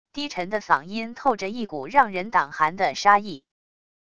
低沉的嗓音透着一股让人胆寒的杀意wav音频